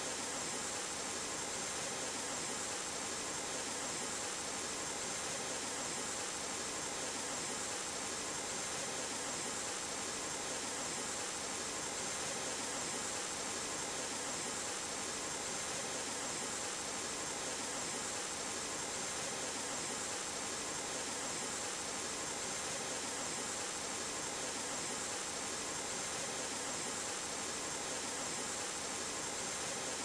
apu_inside.wav